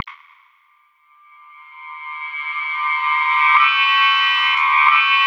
BLIP      -R.wav